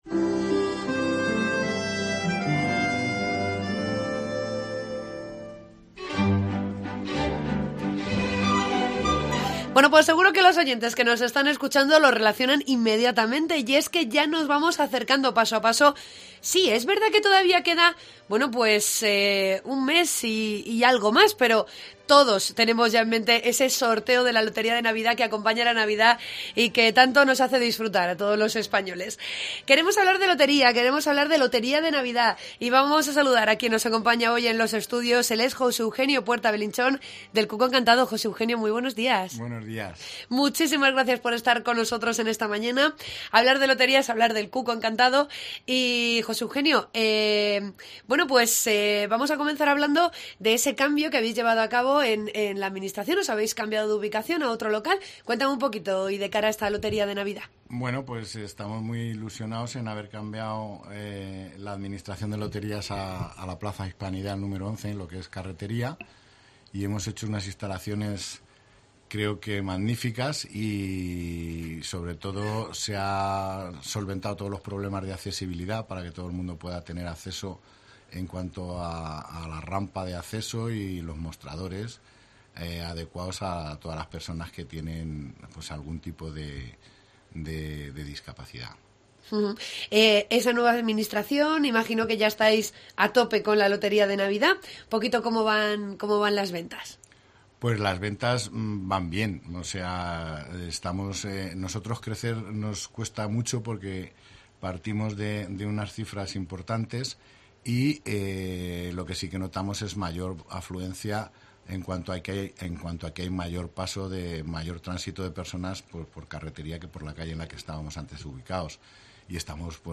Entrevista sobre la lotería de Navidad